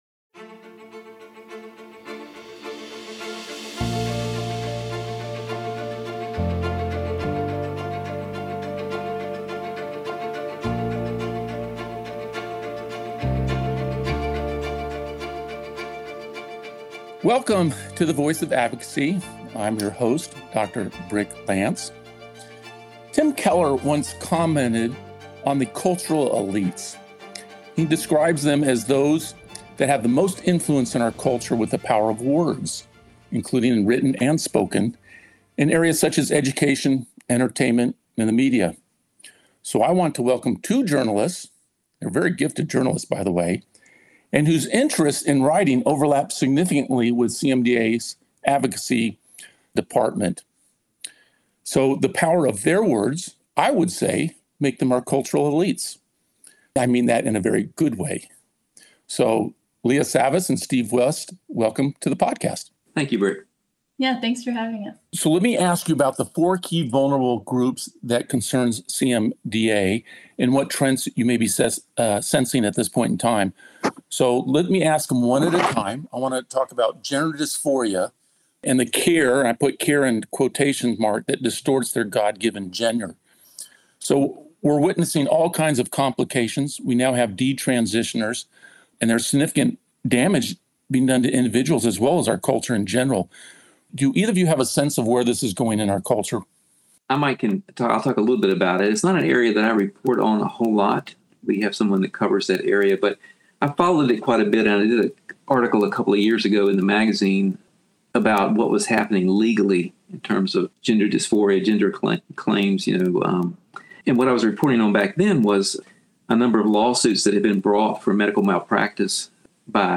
Interviewing the Interviewers of WORLD News Group